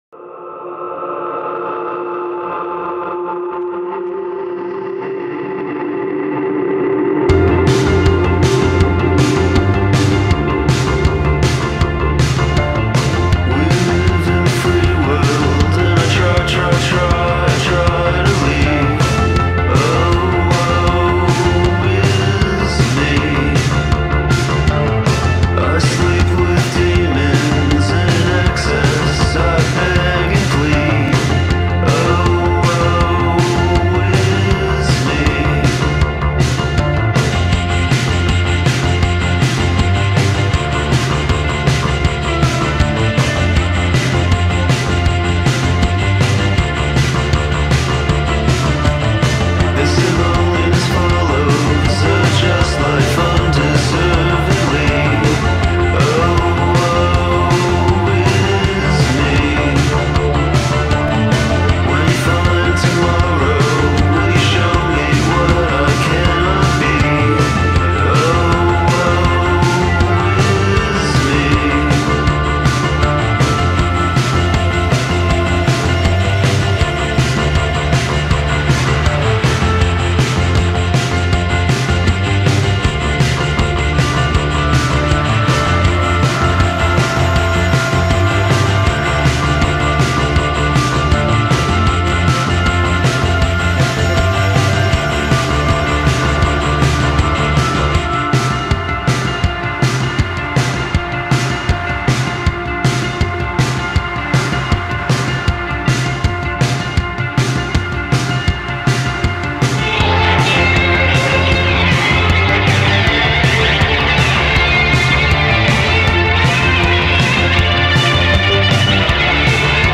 sounds like all harakiri diat bands combined into one